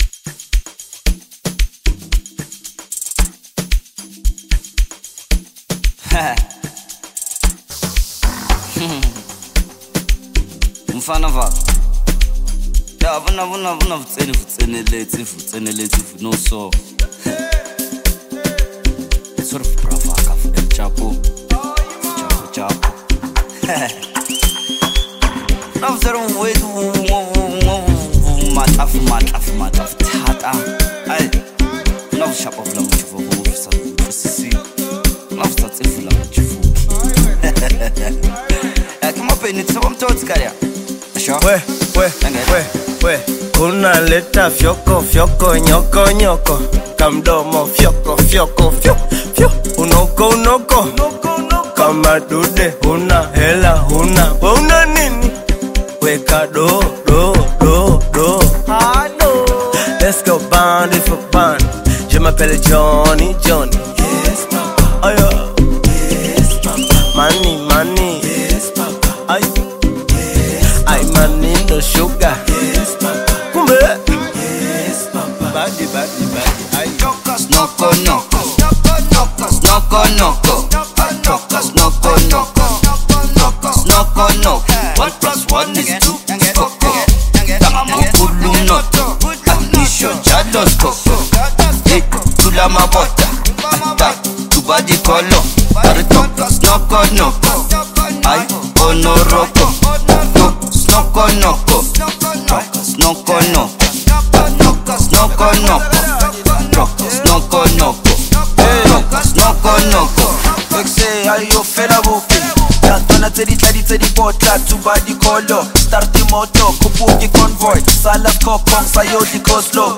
energetic Amapiano/Afro-dance collaboration
chant-style lyrics
pulsating Amapiano log-drum rhythms
cross-continental dance anthem